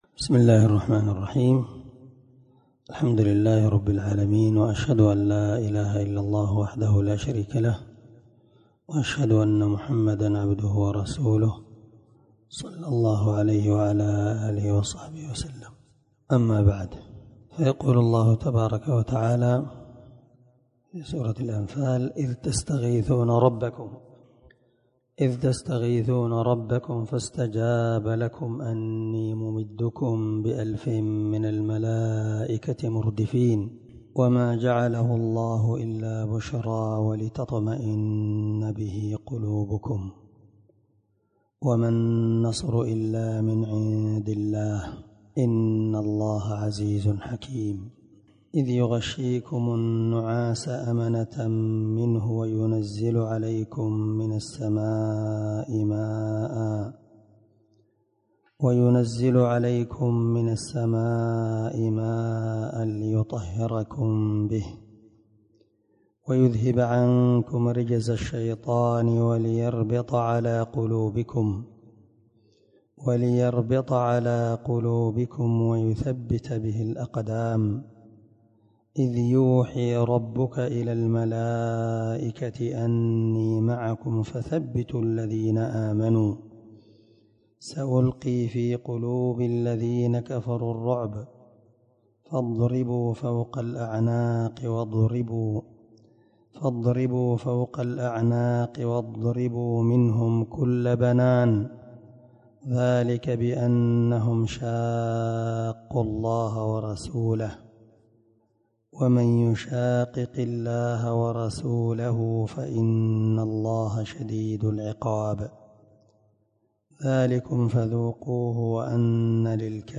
507الدرس 4 تفسير آية ( 9 – 14 ) من سورة الأنفال من تفسير القران الكريم مع قراءة لتفسير السعدي
دار الحديث- المَحاوِلة- الصبيحة.